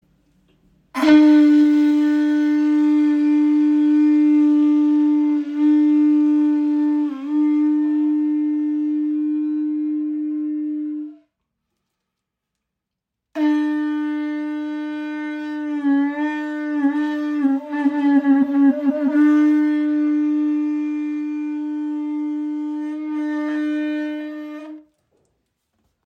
• Poliert: Sanftes Mundstück für komfortables Spielen bei kraftvollem, tragendem Klang.
Kuhhorn - Signalhorn poliert Nr. 3